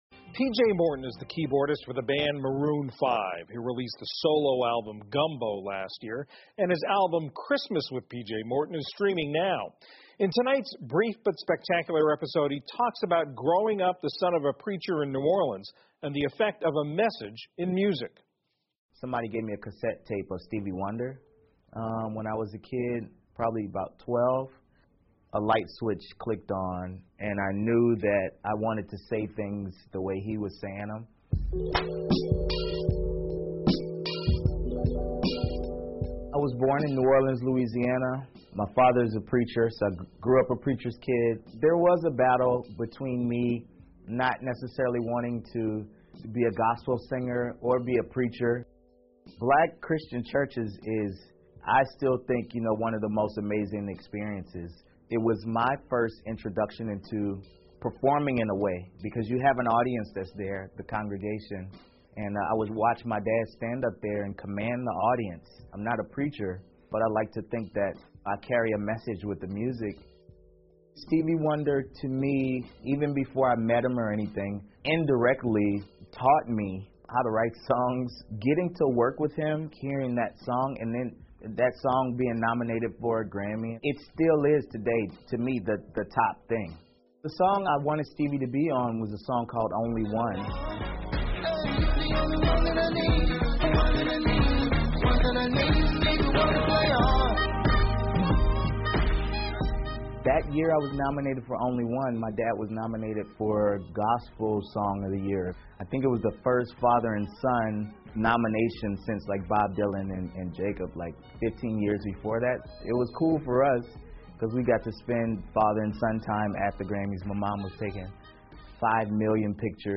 PBS高端访谈:PJ·莫尔顿的音乐人生 听力文件下载—在线英语听力室